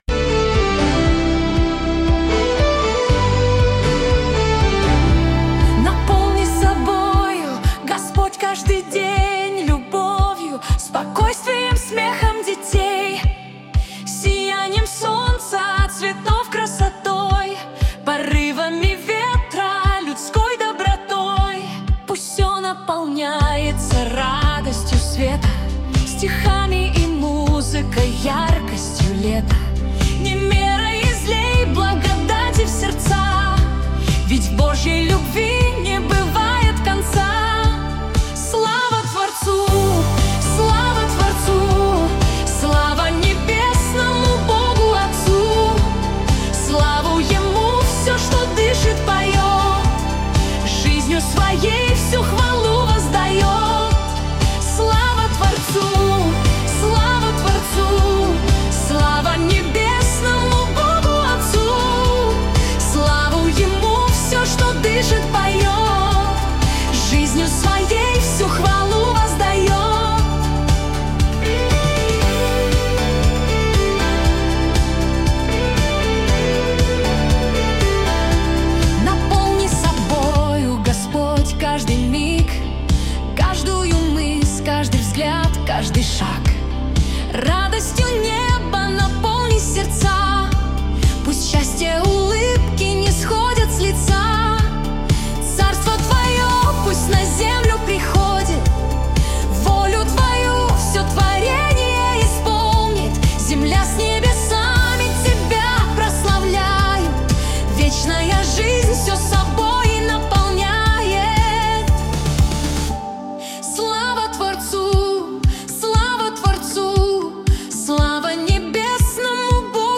песня ai
144 просмотра 681 прослушиваний 43 скачивания BPM: 118